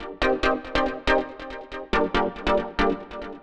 Index of /musicradar/future-rave-samples/140bpm
FR_Minee_140-C.wav